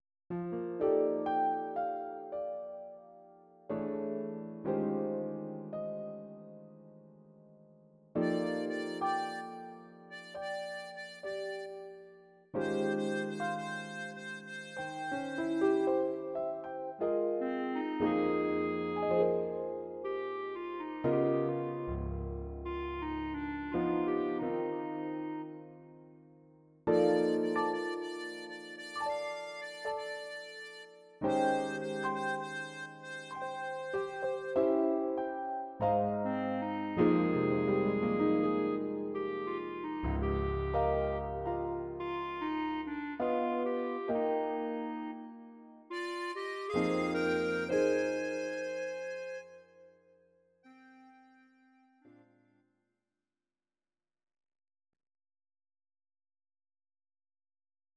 Please note: no vocals and no karaoke included.
Your-Mix: Jazz/Big Band (731)